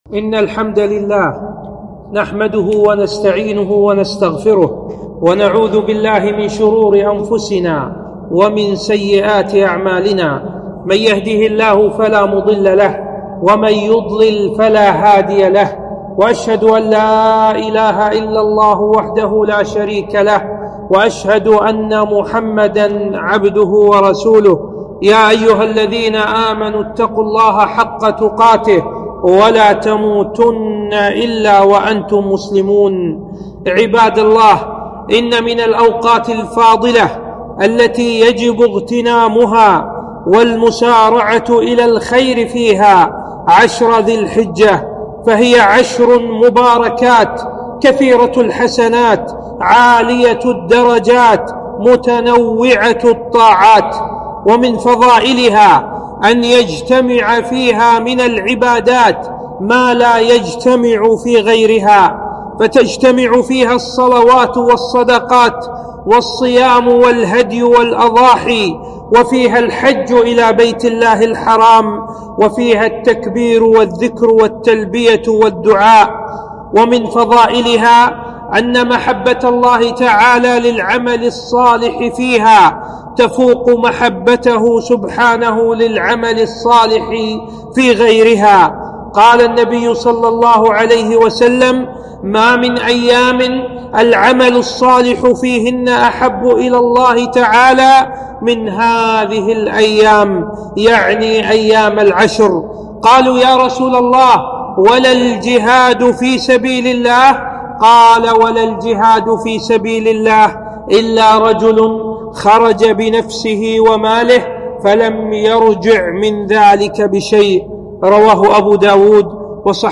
خطبة - فضل عشر ذي الحجة والأضحية